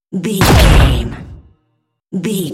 Dramatic hit drum thunder
Sound Effects
Atonal
heavy
intense
dark
aggressive
hits